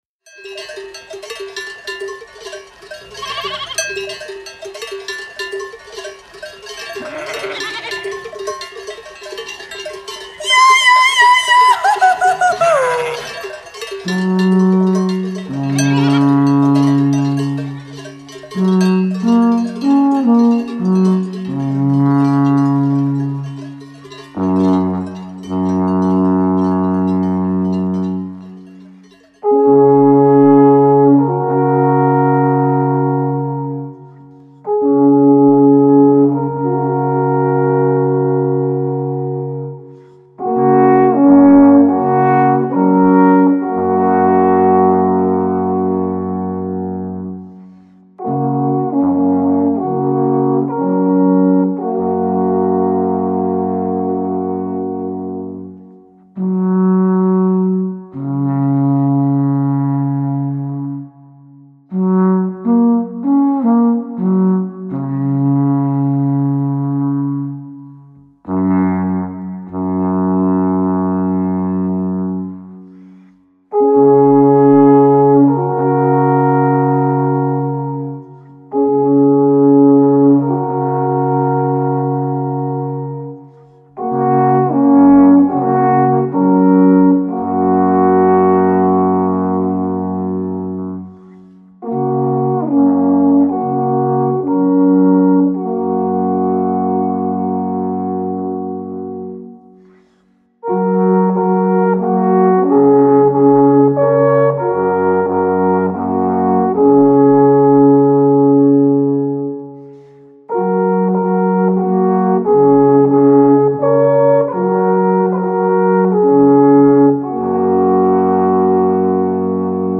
Genuine folk music from Central Switzerland.
Alphorn melodies, Swiss folk dances and songs.
Kärnser Michel. Alphornmelodie.
Alphorn-Trio vom Alpstubli